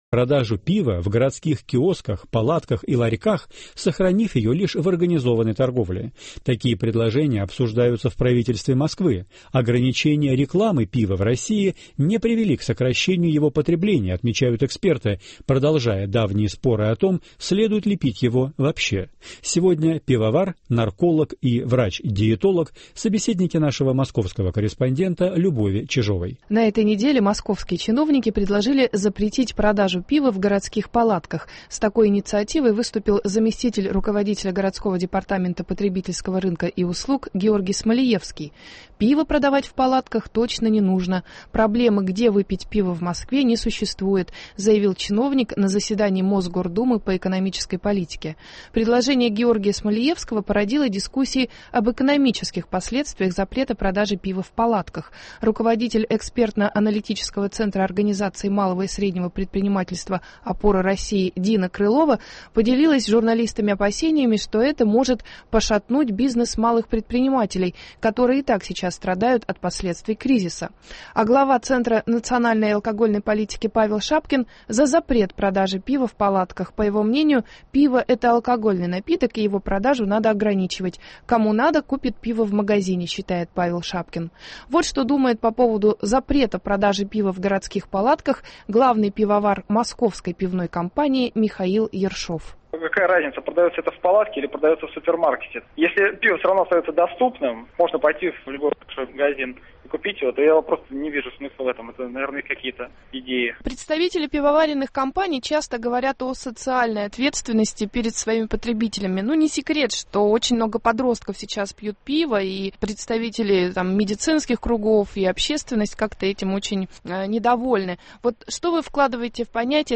Пиво: пить или не пить? Спорят пивовар, врач-нарколог и врач-диетолог.